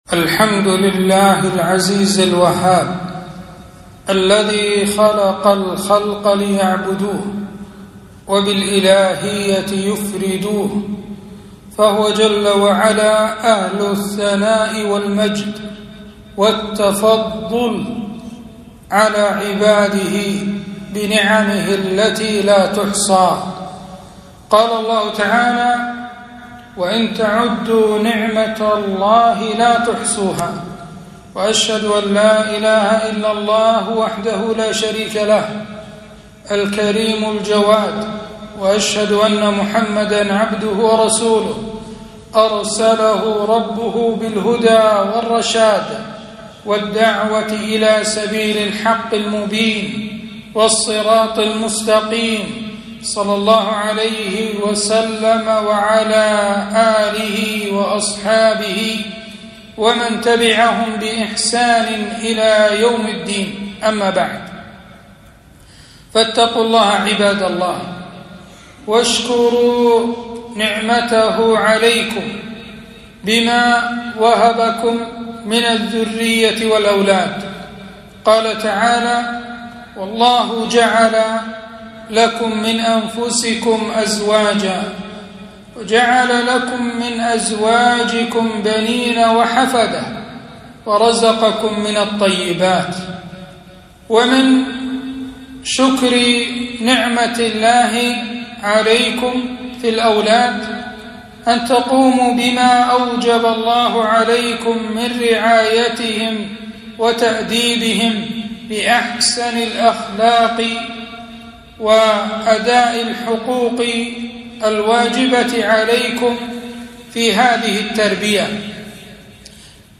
خطبة - تربية الأولاد في الإسلام